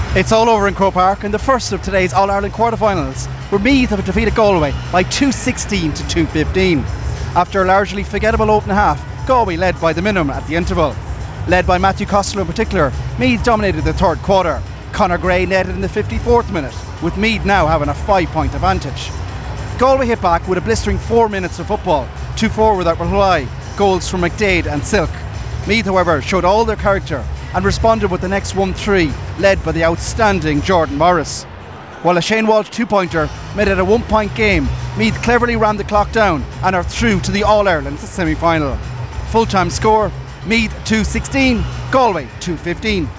full time report from GAA Headquarters